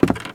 STEPS Wood, Creaky, Walk 04.wav